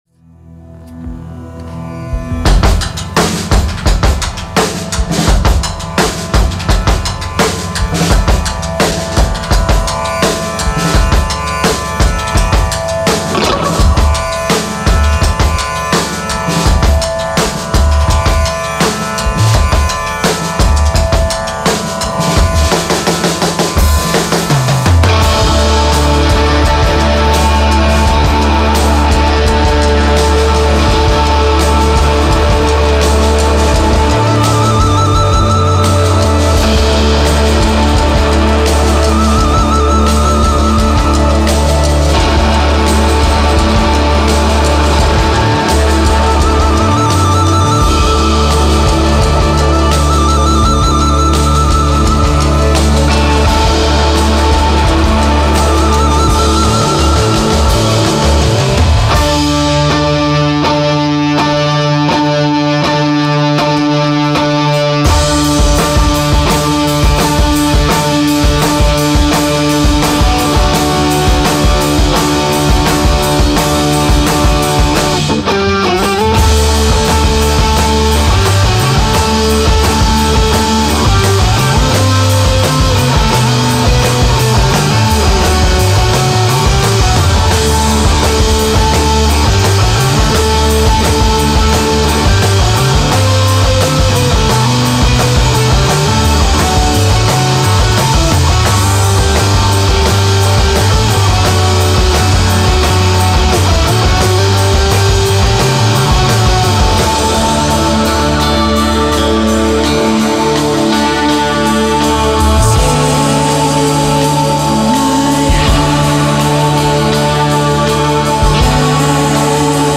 are a three-piece band
New-Psychedelia